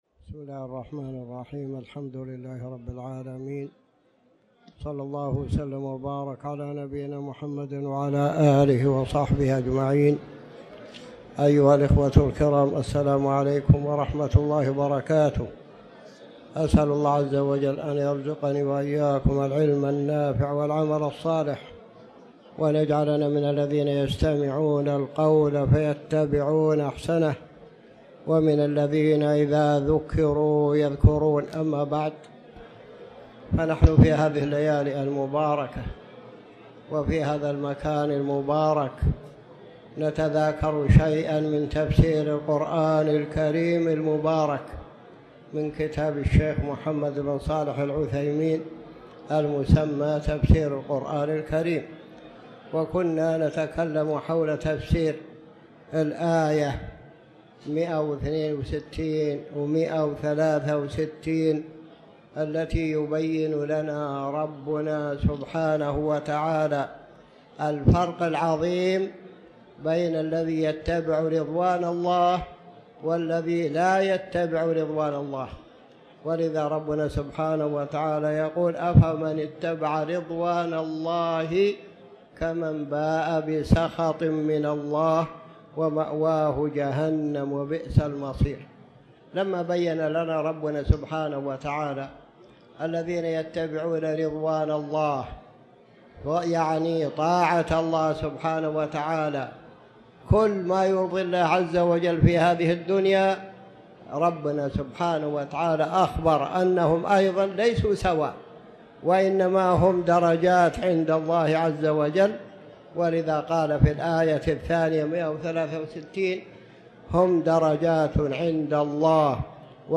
تاريخ النشر ١١ ربيع الأول ١٤٤٠ هـ المكان: المسجد الحرام الشيخ